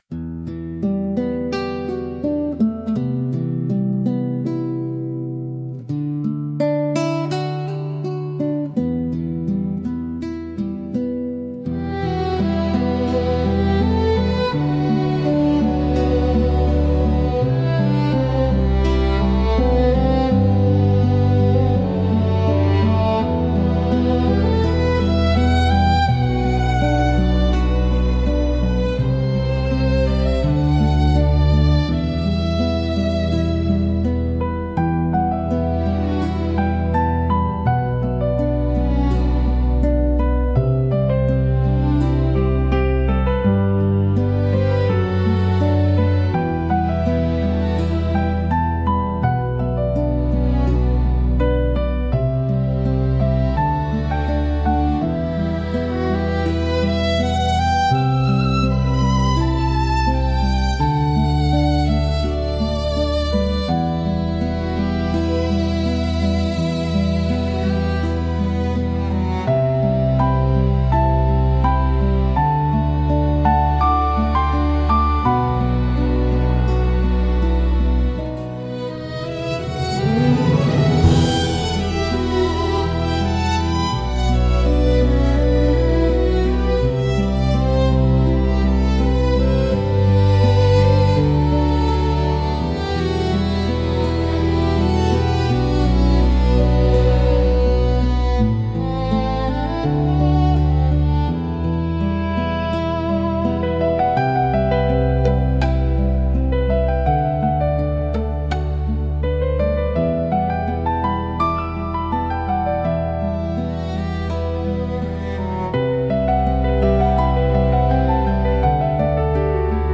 聴く人に共感や切実な感情を与え、場の雰囲気を切なく美しく彩るジャンルです。